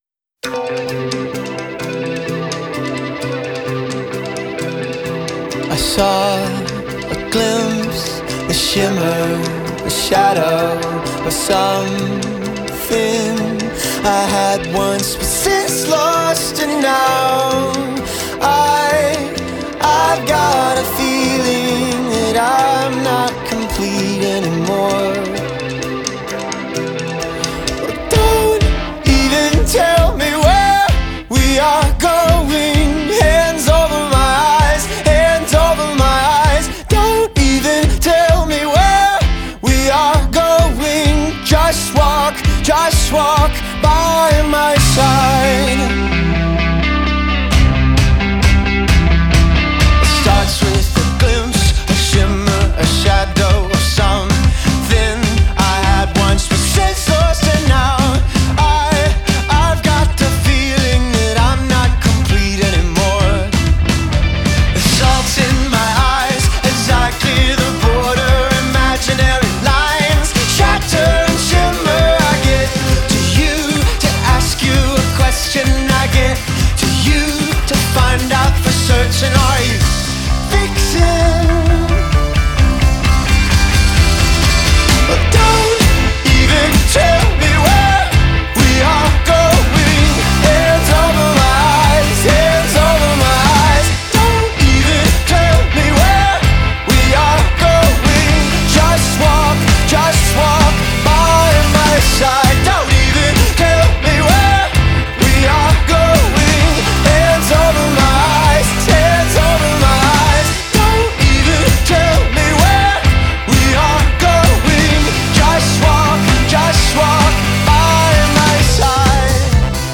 Genre: Indie